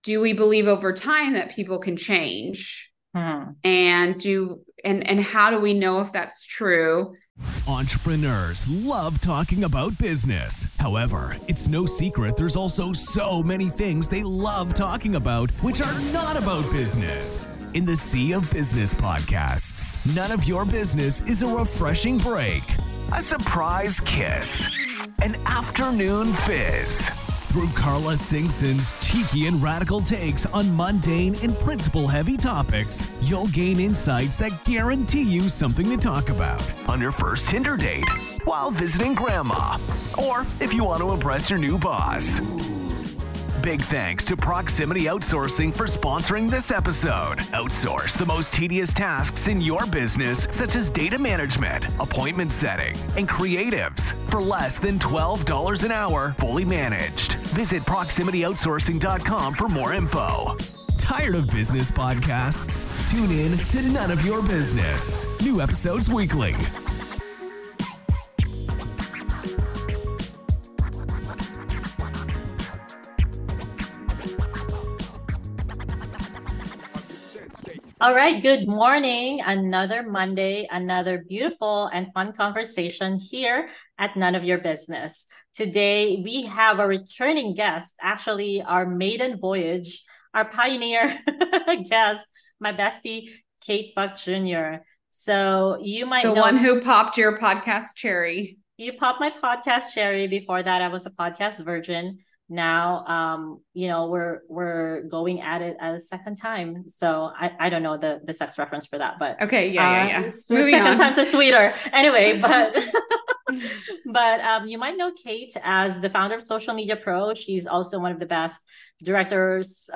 Pour out a warm beverage, maybe get your journal out, and listen to this hearty conversation.